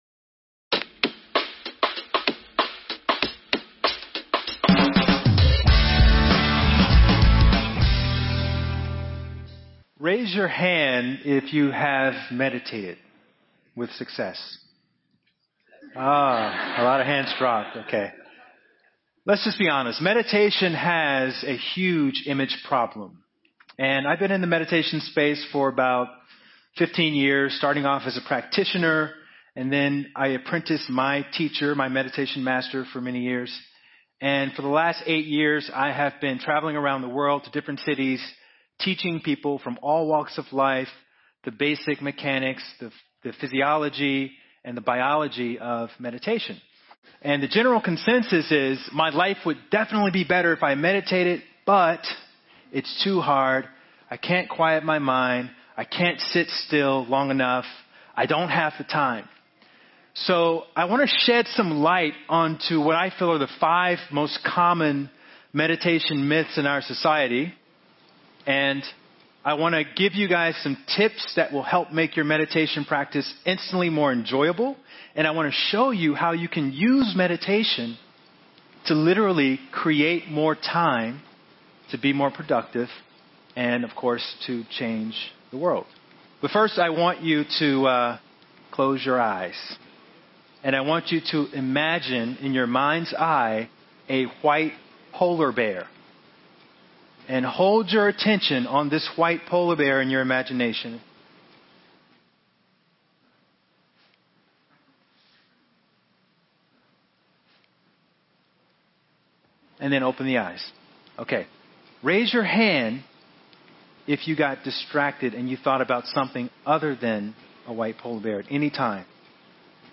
This event took place on February 22, 2015 at Los Angeles, California.